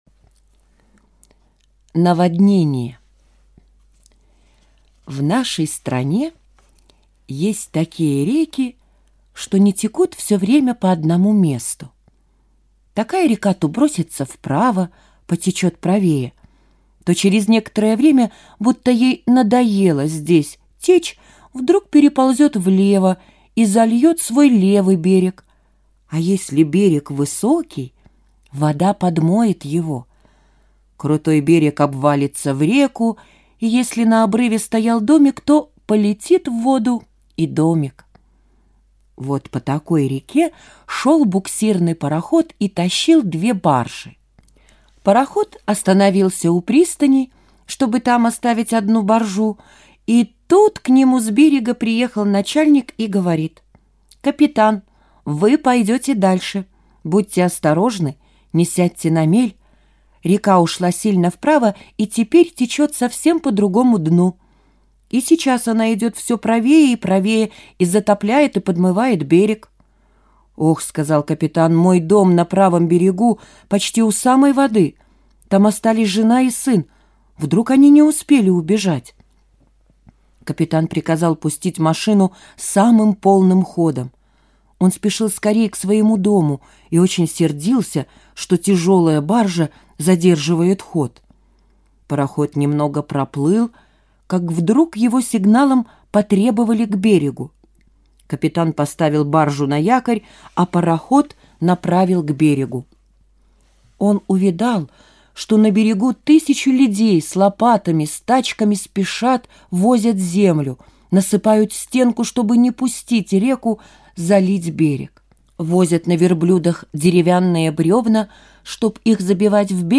Аудиорассказ «Наводнение»